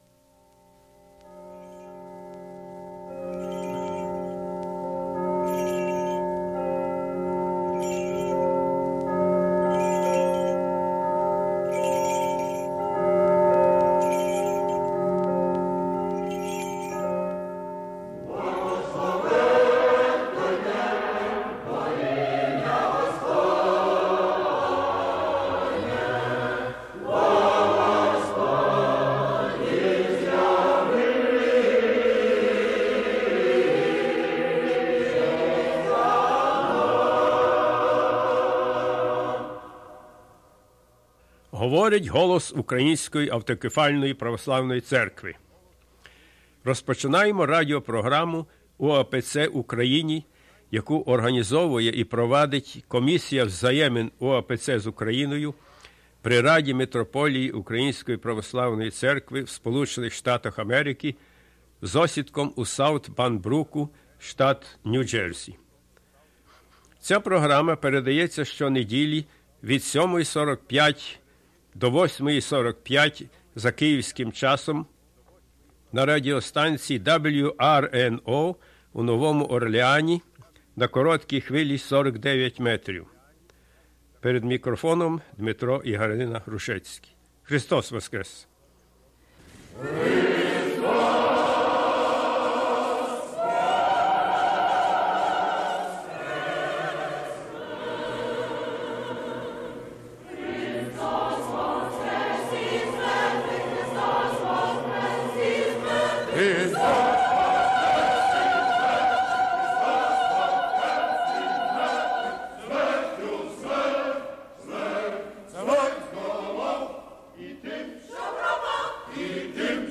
Gospel reading and "Spiritual Discussion"
Choral rendition of the conclusion of the Divine Liturgy